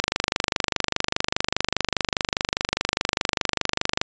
Para ello, se emplean fuentes de sonido monoaural semejantes a un ruido crepitoso. Un ejemplo de este sonido, el que percibe el usuario, se puede ver aquí . Estas fuentes sonoras se procesas mediante la aplicación de las HRTF's pertinentes según el esquema ya visto, y se reproducen en los oídos del sujeto.